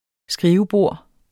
Udtale [ ˈsgʁiːvəˌboˀɐ̯ ]